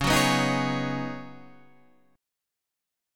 C# 9th Suspended 4th